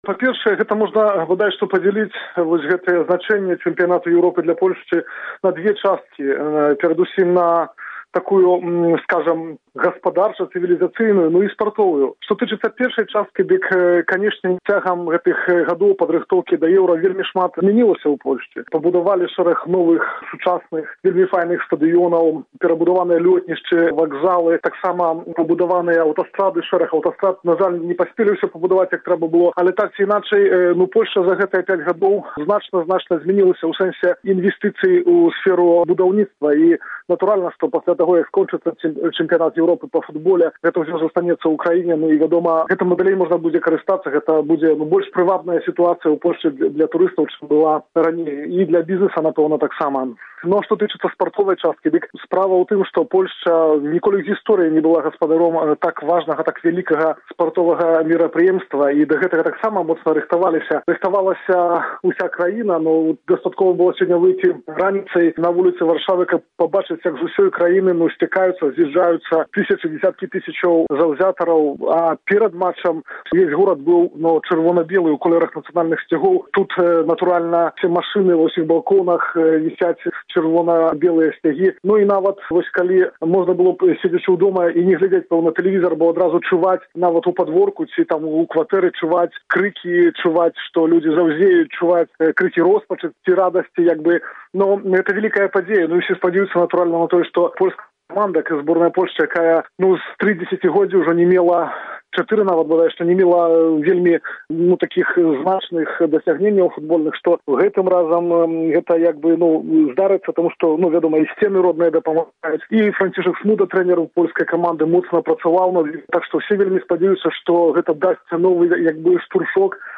Рэпартаж з Варшавы, 8 чэрвеня 2012 году